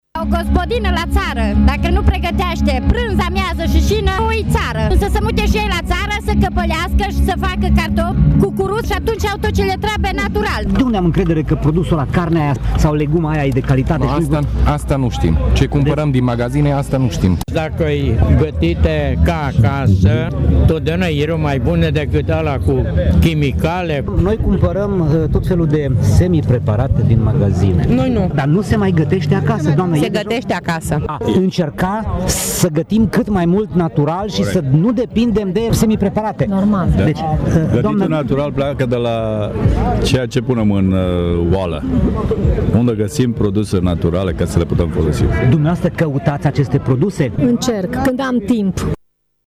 Mulți târgumureșeni au spus că e necesar să se revină la mâncărurile gătite acasă și a se evita, pe cât posibil, semipreparatele sau produsele pline de conservanți din magazine: